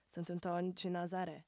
Speech
Valei-me Metronome